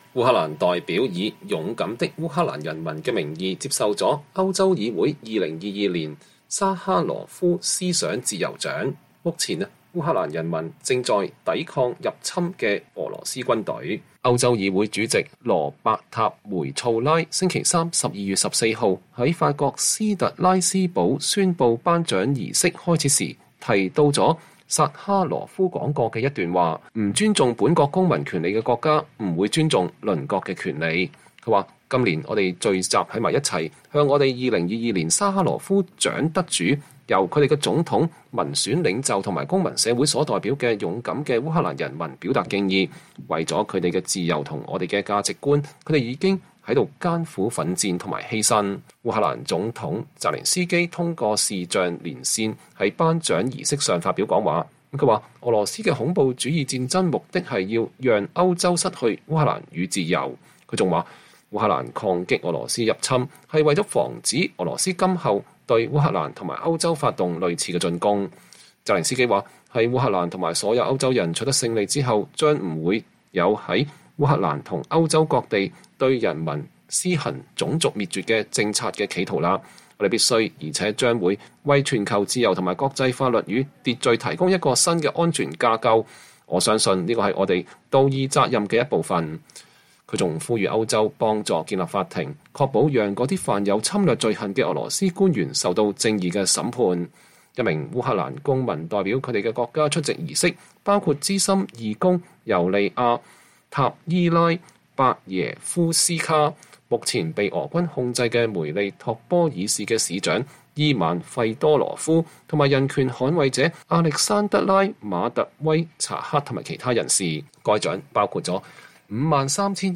烏克蘭人民的代表在斯特拉斯堡舉行的頒獎儀式上領取歐洲議會薩哈羅夫獎。(2022年12月14日)